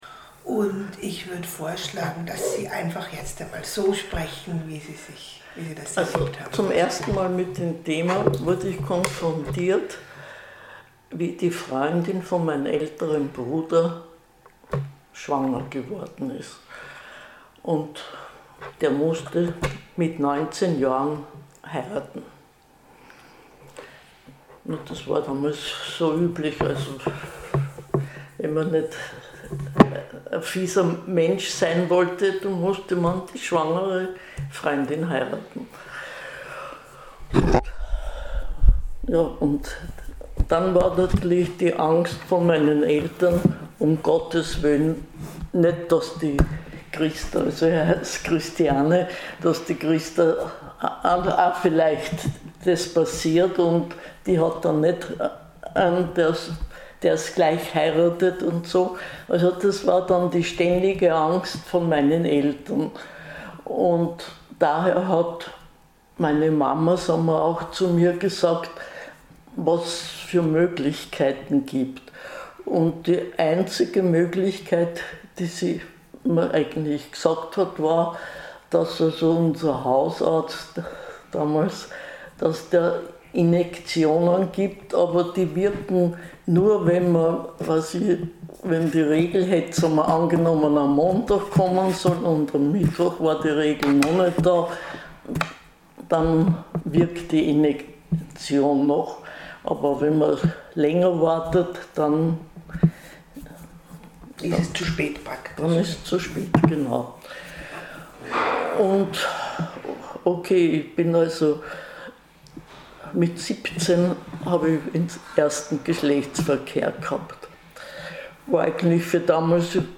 Das Interview wurde gekürzt und anonymisiert.